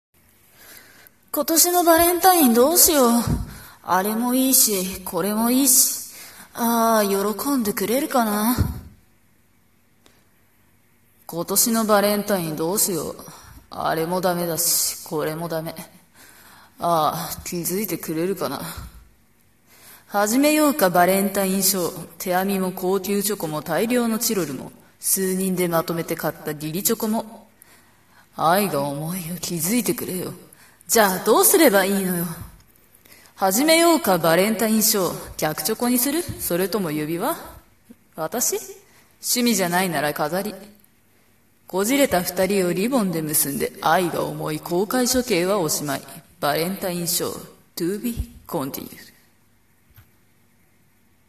【自作詩朗読】